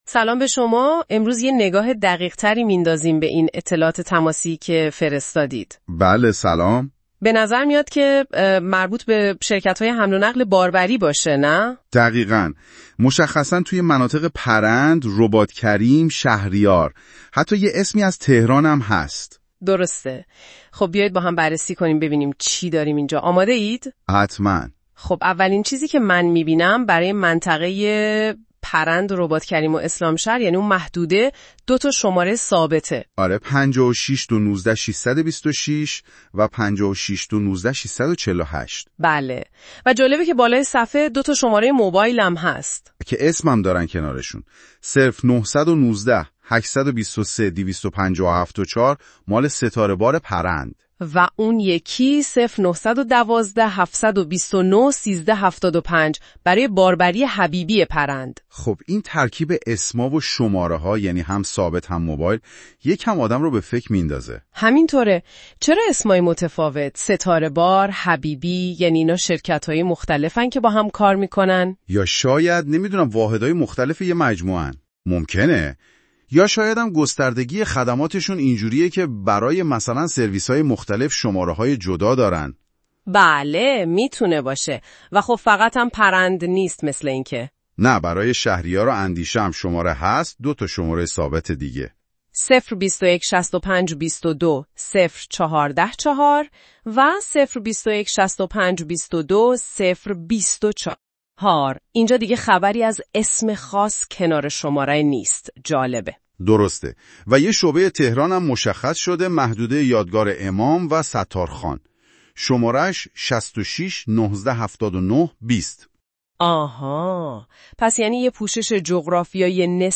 پادکست جذاب زیر خلاصه ای از کل محتوای این صفحه است که با هوش مصنوعی تولید شده: